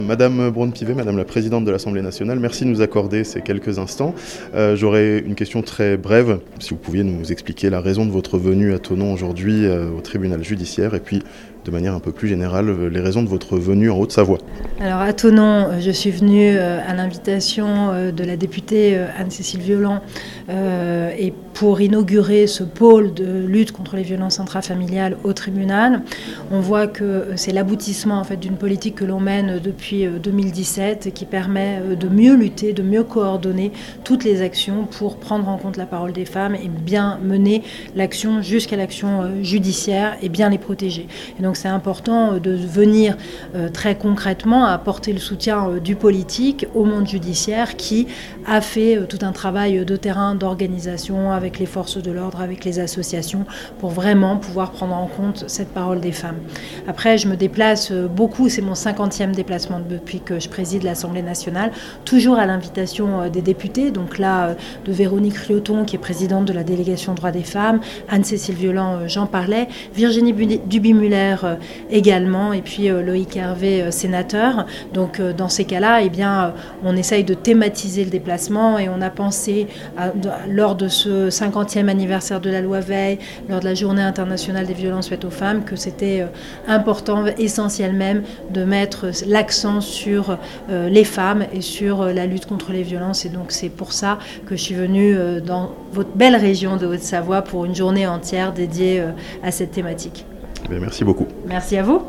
La présidente de l'Assemblée Nationale Yaël Braun-Pivet était à Thonon ce vendredi (interview)
braunpivet-66553.mp3